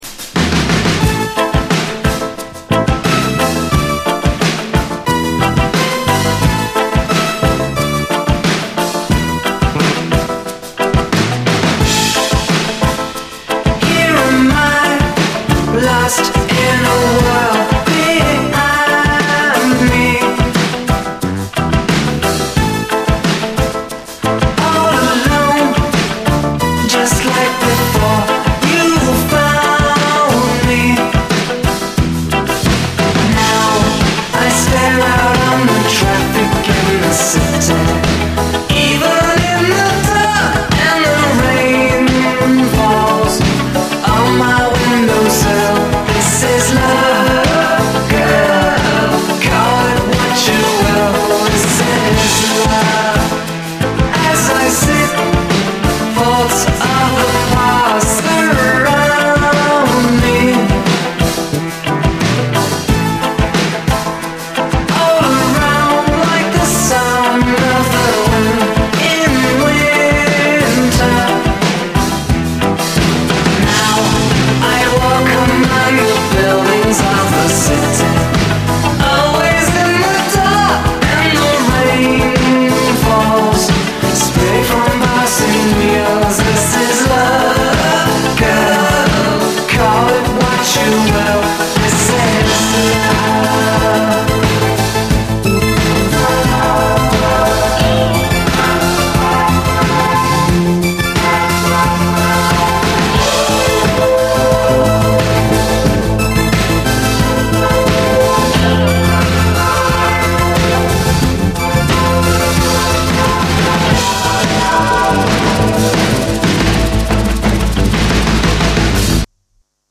69年のソウルフル・ラテン・ジャズ〜ファンク！
甘美でソウルフルでクールな見過ごせない一曲！
STEREO